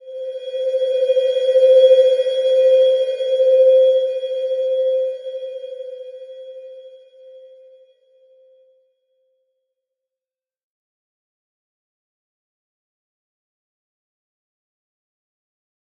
Slow-Distant-Chime-C5-f.wav